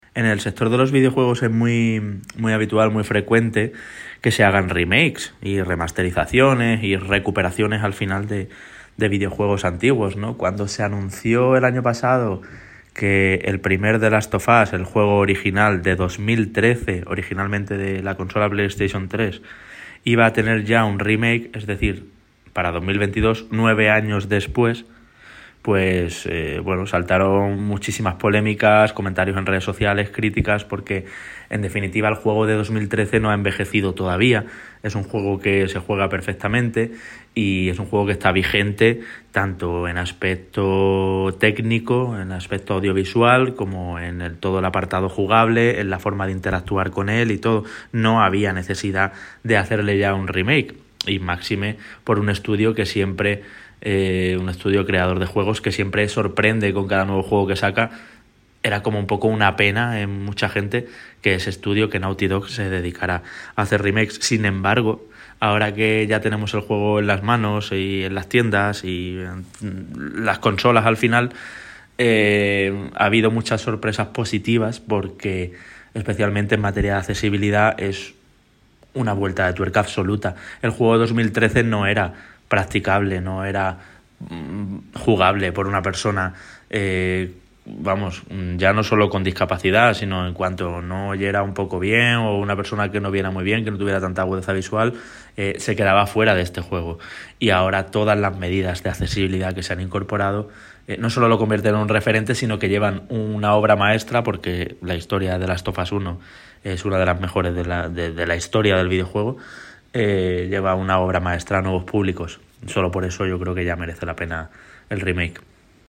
periodista especializado en videojuegos y accesibilidad